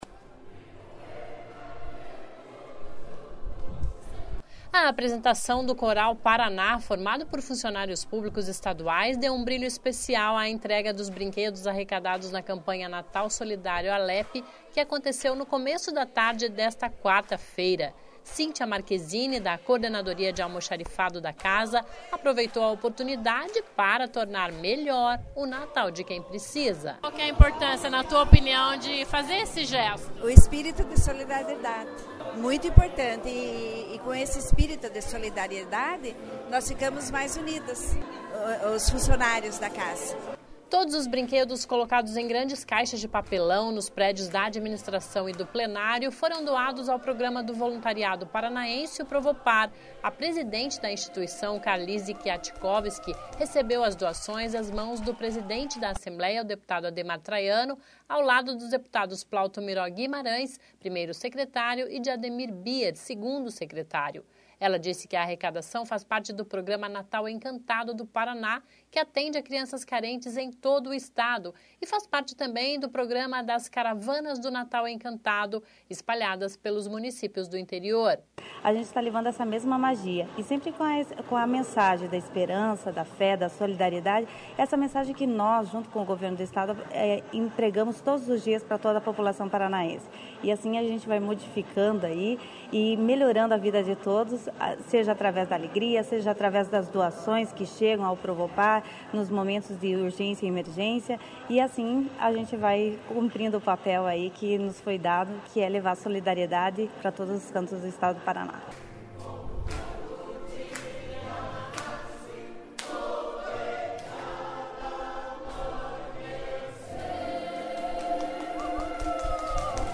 (Começa com sobe som)
(Volta  sobe som)